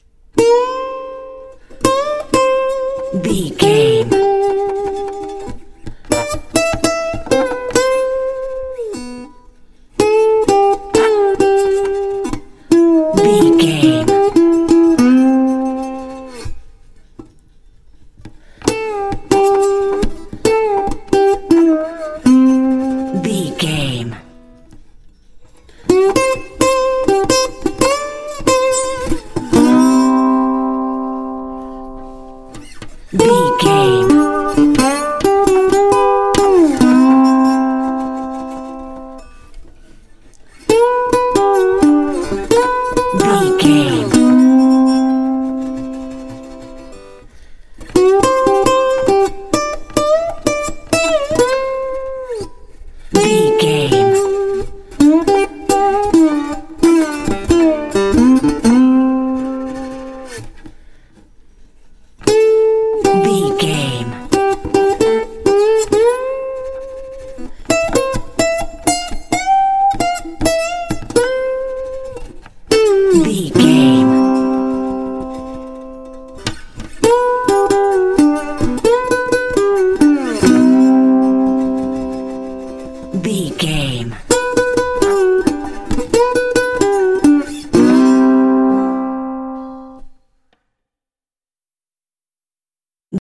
Ionian/Major
happy
acoustic guitar
playful